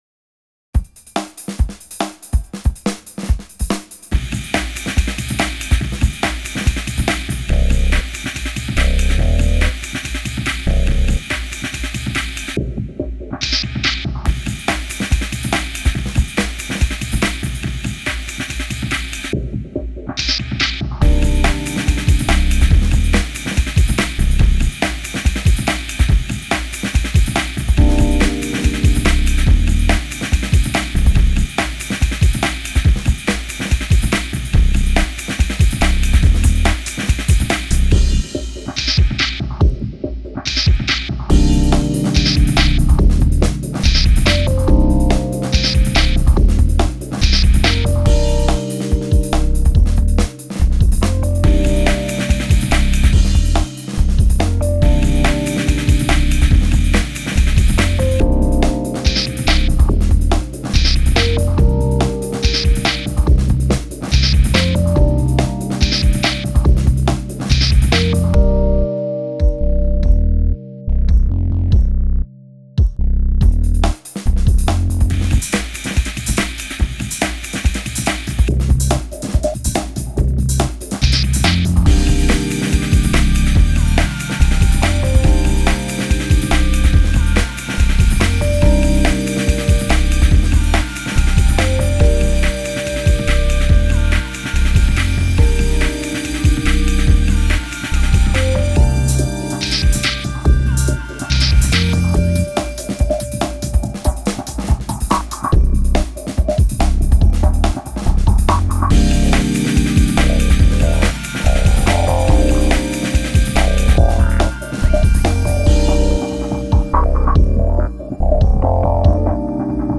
Genre Laidback